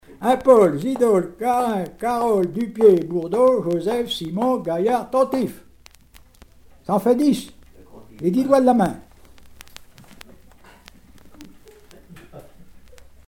formulette enfantine : jeu des doigts
Genre brève